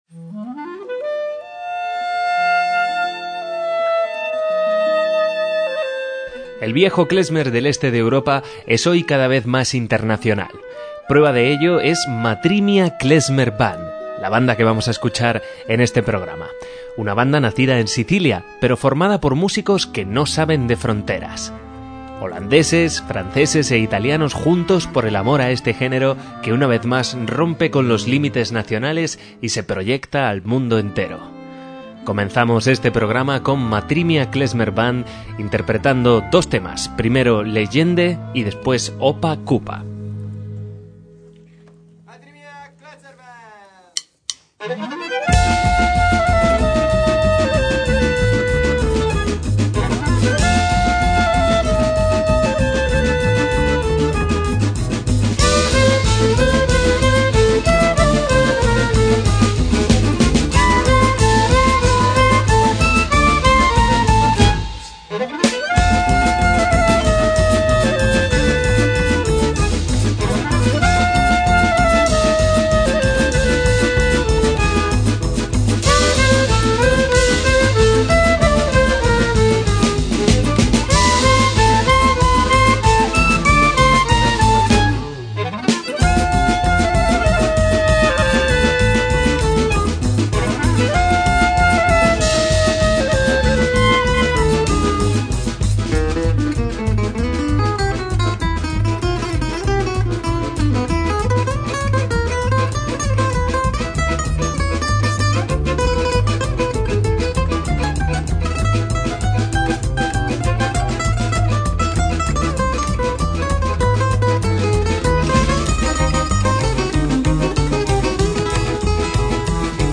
MÚSICA KLEZMER - El sonido de Matri mia es una mezcla extraordinaria y cautivadora de música gitana, balcánica, árabe y judía con influencias de la canción francesa, el jazz manouche, la música mediterránea pero también el reggae, la electrónica, el ska y el pop rock.